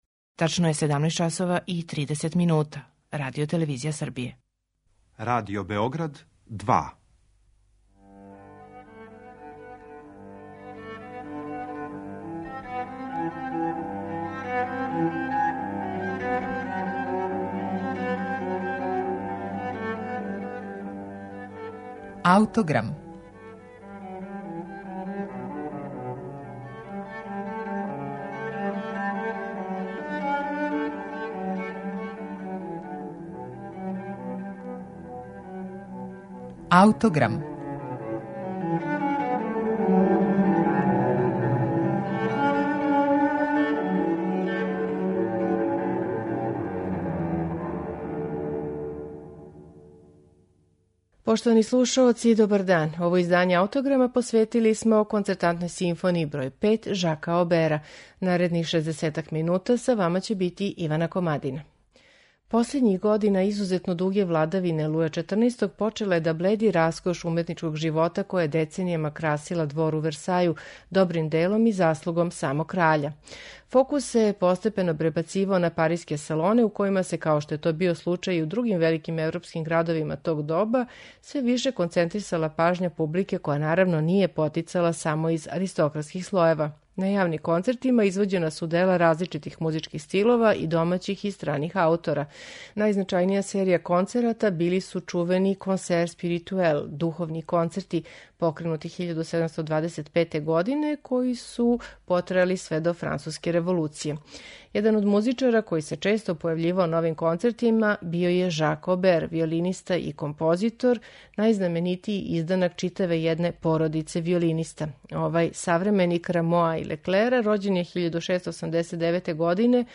Концертантна симфонија број 5 у Еф-дуру Жака Обера
Данашњи Аутограм посветили смо Концертантној симфонији број 5 у Еф-дуру Жака Обера, коју ћете слушати у тумачењу ансамбла Колегијум музикум 90 , под управом Сајмона Стандиџа.